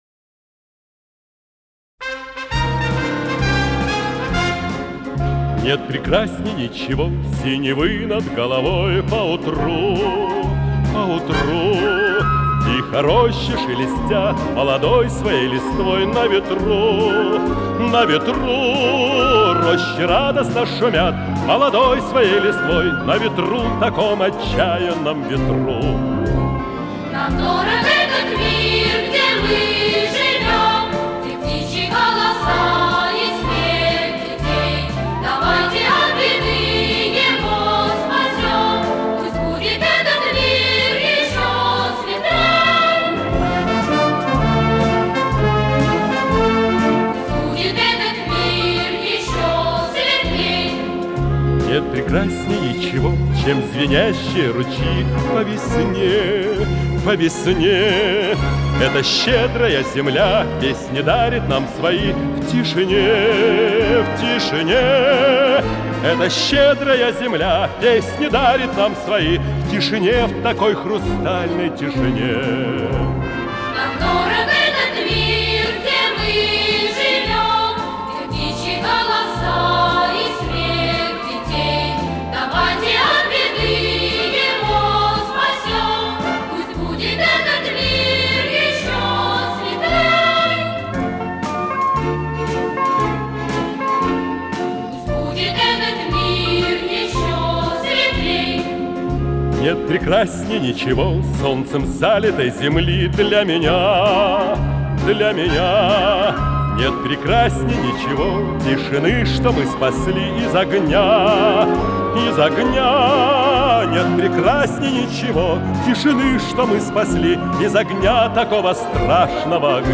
баритон
Скорость ленты38 см/с
ВариантДубль моно